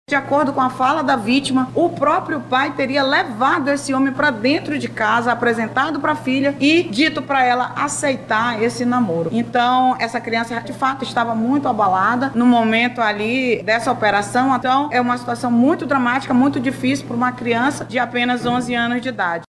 Ainda segundo a delegada, a vítima relatou que foi o próprio pai que levou o homem para viver com ela.